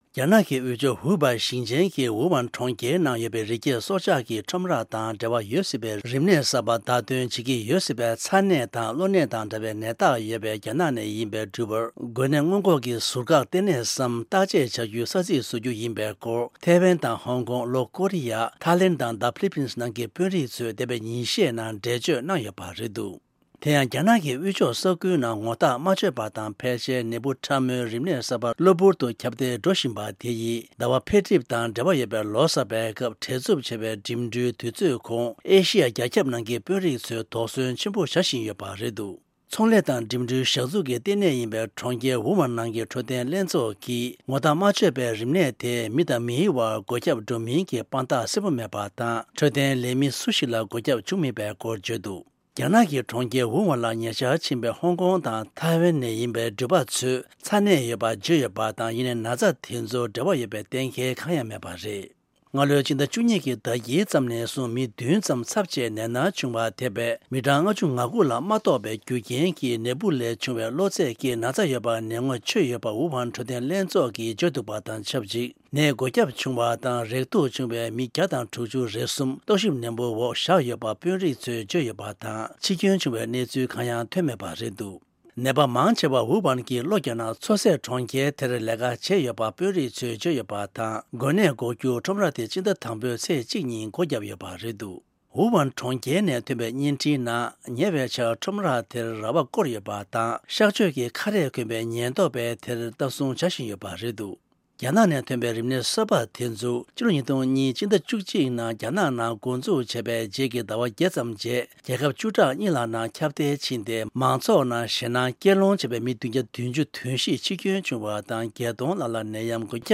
རྒྱ་ནག་ཡུལ་དབུས་ནང་ངོ་ཐག་མ་ཆོད་པའི་ནད་འབུ་ཕྲ་མོའི་རིམས་ནད་གསར་པ་གློ་བུར་དུ་ཁྱབ་གདལ་འགྲོ་བཞིན་པ་དེ་ཡིས་ཟླ་ངོའི་ལོ་གསར་སྐབས་ཀྱི་བྲེལ་འཚབ་ཆེ་བའི་འགྲིམ་འགྲུལ་དུས་ཚོད་གོང་ཨེ་ཤི་ཡ་ནང་རྒྱ་ཆེར་ཁྱབ་གདལ་དུ་འགྲོ་སྲིད་པའི་དོགས་པ་ཆེན་པོ་བྱེད་བཞིན་ཡོད་པའི་སྐོར། གསར་ཤོག་ནང་ཐོན་པའི་གནས་ཚུལ་ཁག